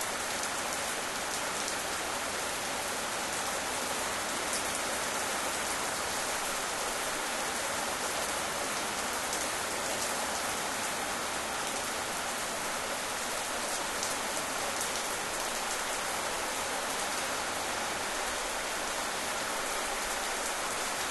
rain_indoors2.ogg